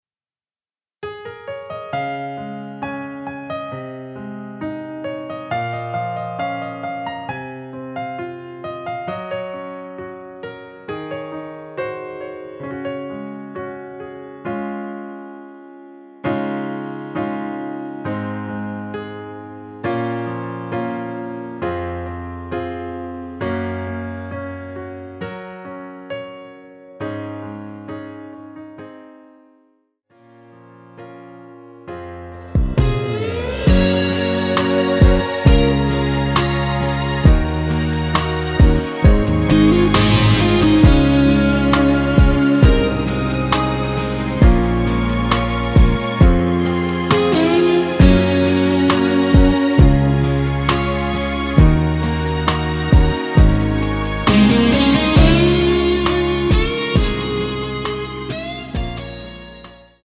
엔딩이 페이드 아웃이라 노래 하시기 좋게 엔딩을 만들어 놓았습니다.
Db
앞부분30초, 뒷부분30초씩 편집해서 올려 드리고 있습니다.